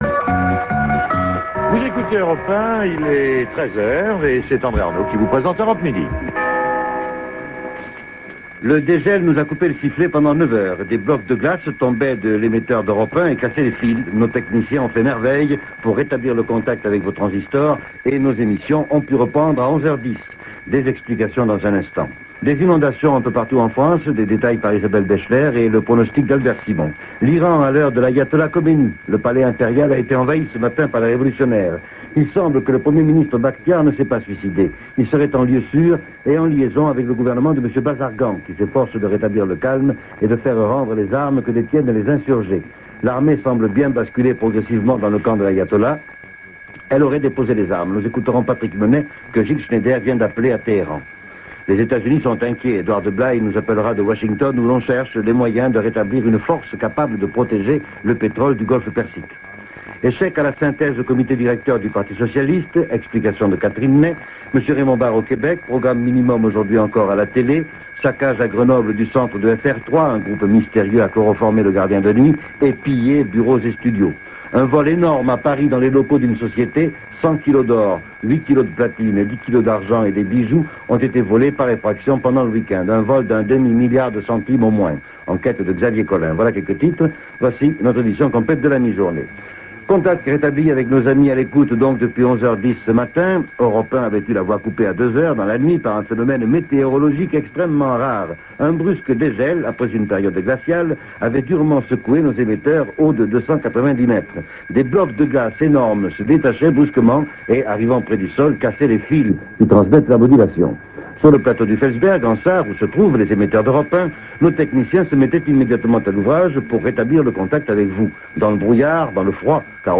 Journal de 13 H